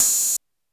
Open Hats
OpenHH SwaggedOut 1.wav